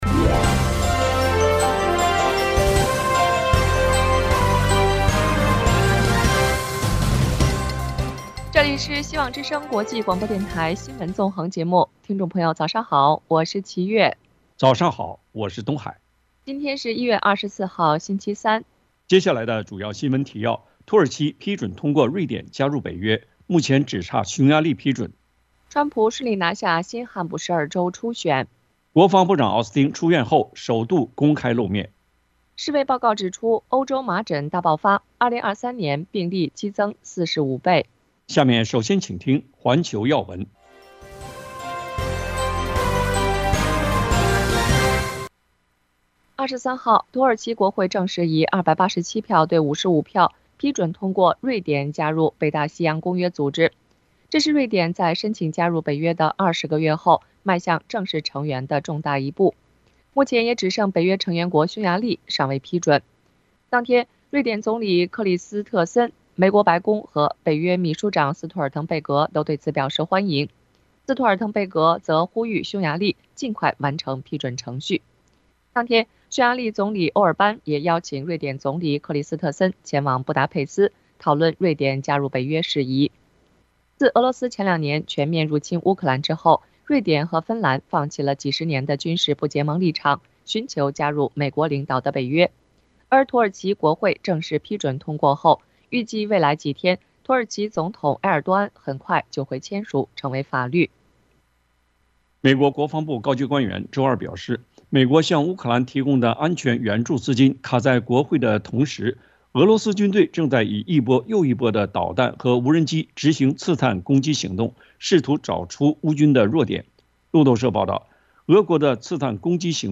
希望之聲 - FM96.1 灣區台